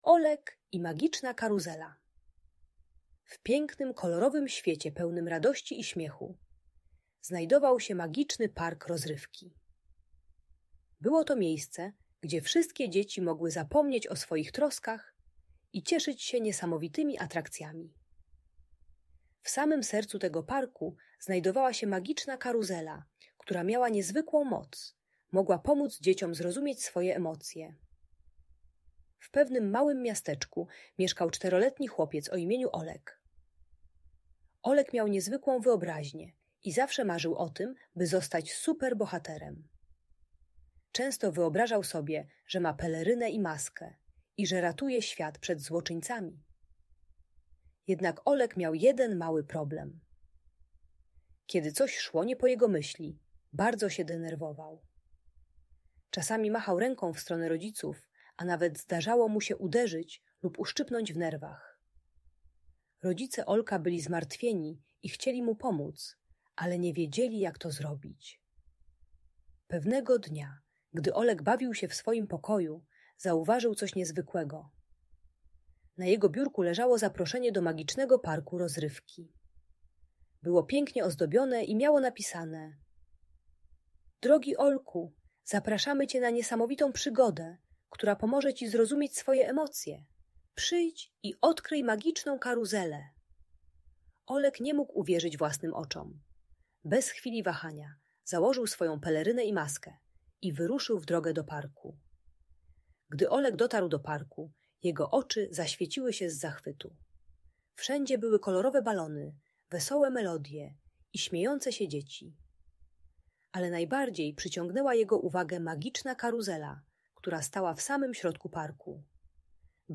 Olek i Magiczna Karuzela - Bajka o Emocjach - Audiobajka dla dzieci